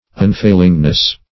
-- Un*fail"ing*ness , n. [1913 Webster]